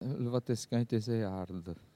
Localisation Notre-Dame-de-Monts
Catégorie Locution